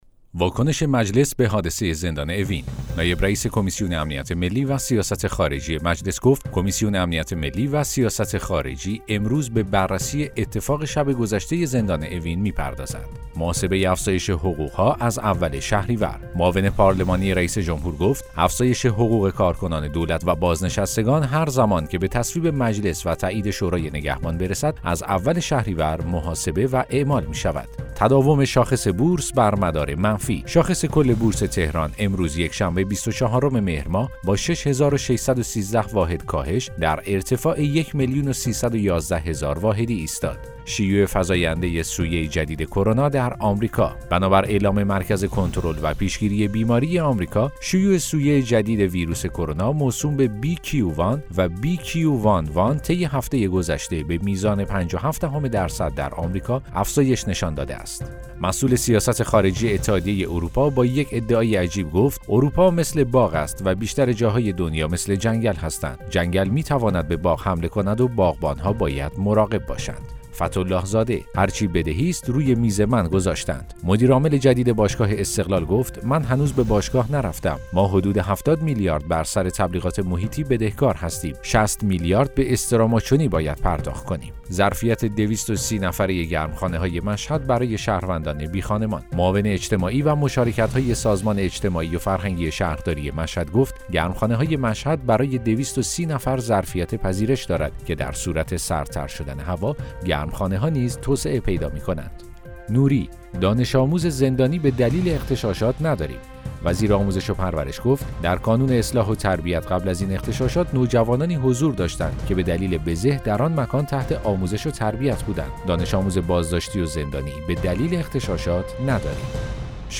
اخبار صوتی - یکشنبه ۲۴ مهر ۱۴۰۱